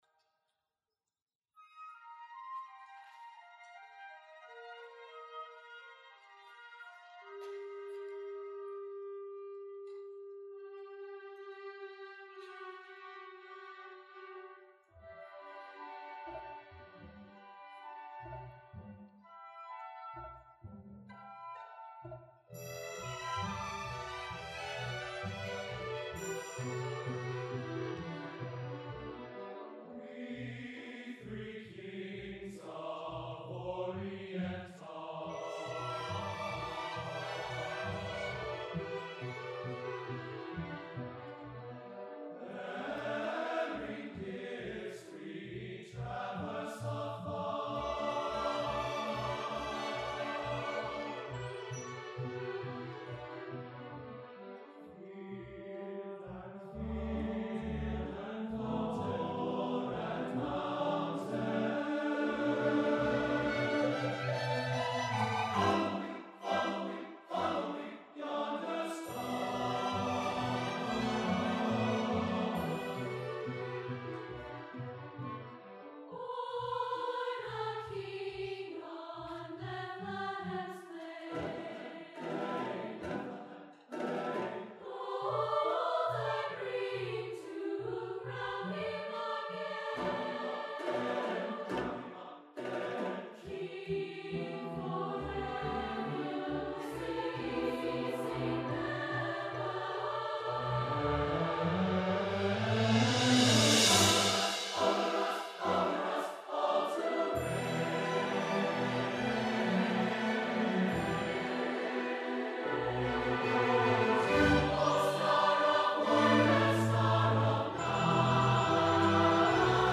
for SATB Chorus and Orchestra (2009)